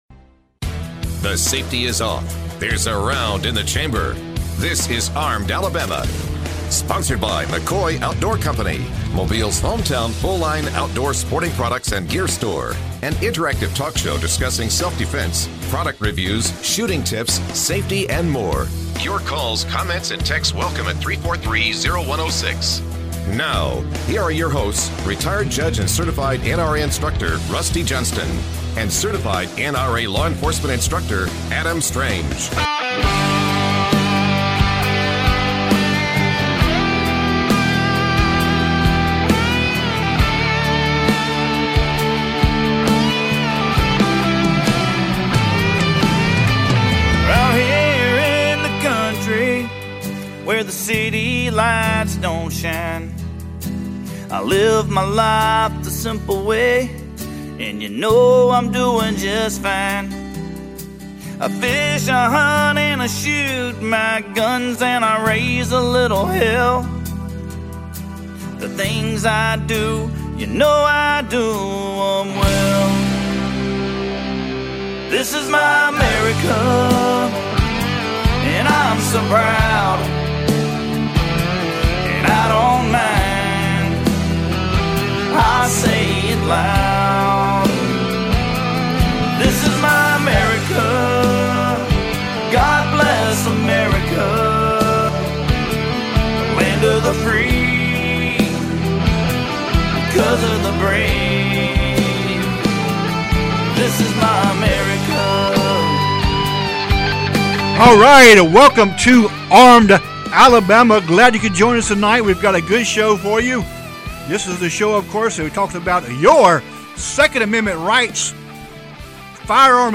Listeners were nvited to share stories of where they were on 9-11.